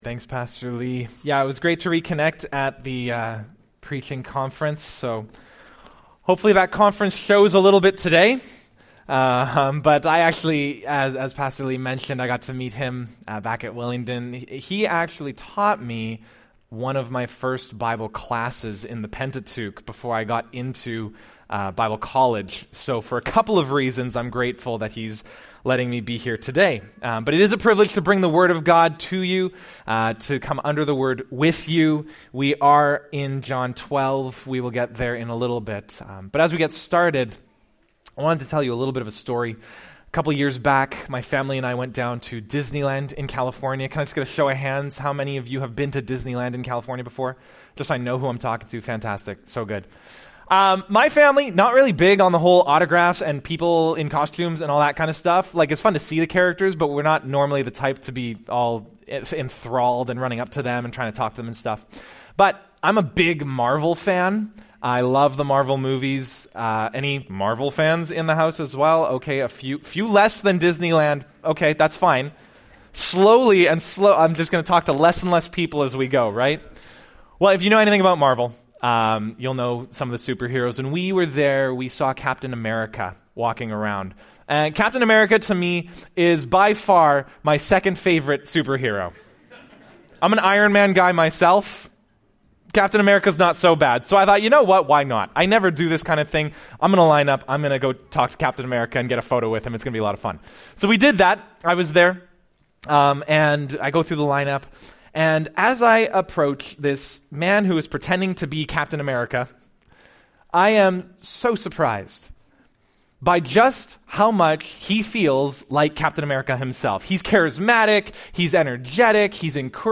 Palm Sunday, 2024 You can follow along in the Bible App , or in the notes section below. Guest speaker